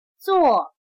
/zuò/Clasificador